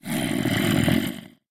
Sound / Minecraft / mob / zombie / say1.ogg